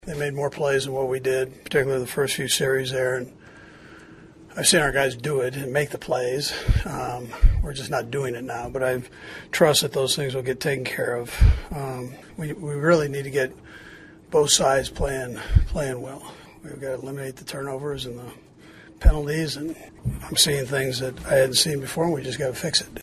Chiefs Coach Andy Reid said they were outplayed.